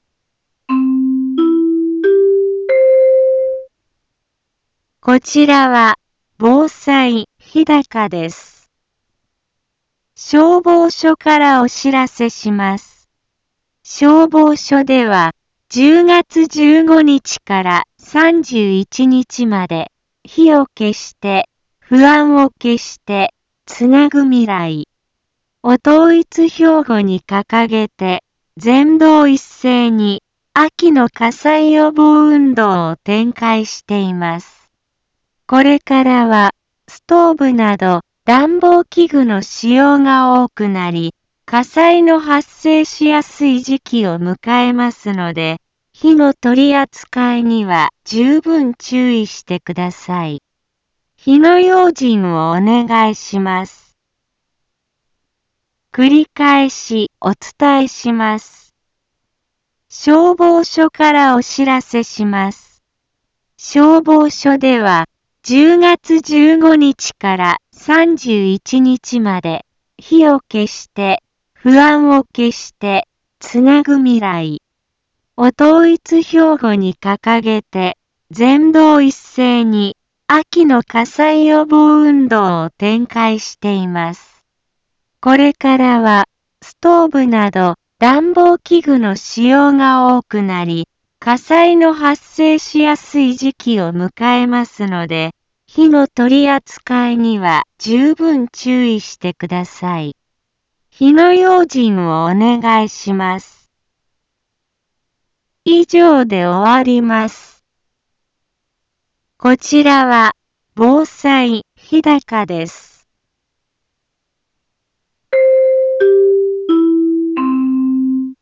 一般放送情報
Back Home 一般放送情報 音声放送 再生 一般放送情報 登録日時：2023-10-16 15:04:09 タイトル：秋の火災予防運動に伴う予防広報について インフォメーション： 消防署からお知らせします。